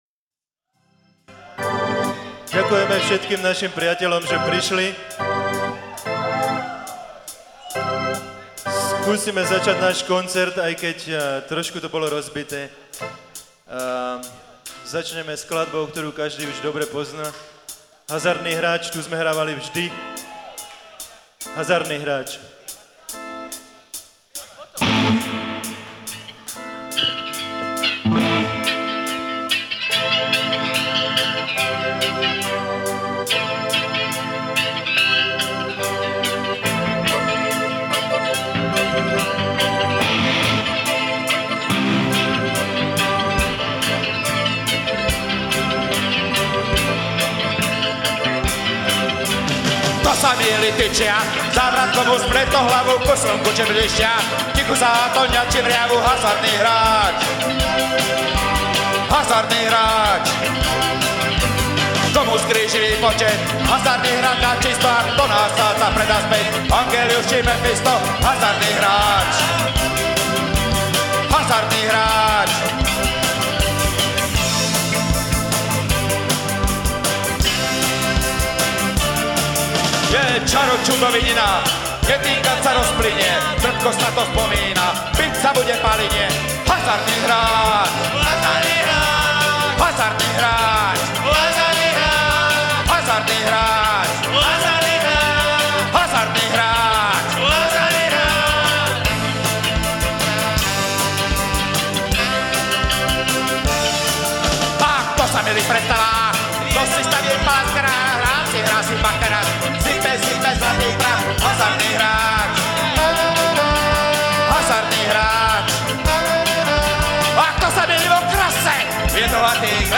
Nahráte: Live Kino Hviezda Trenčín 16.12.2000